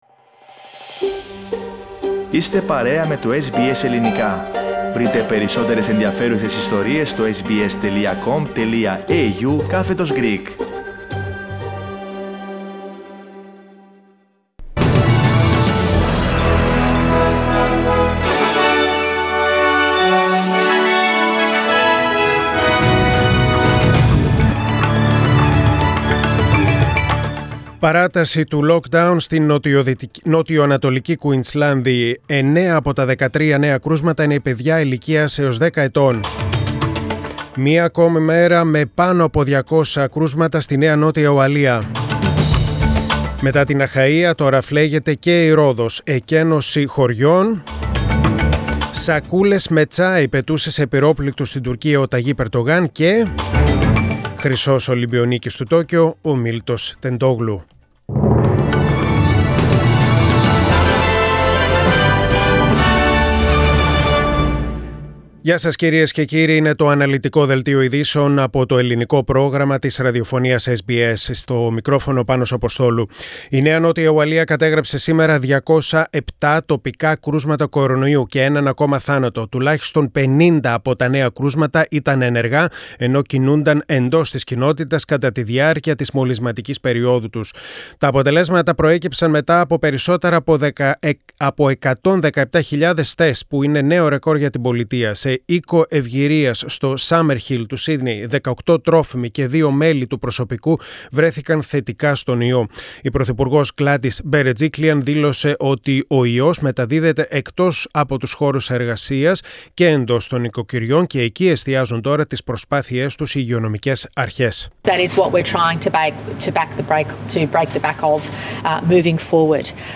News in Greek: Monday 2.8.2021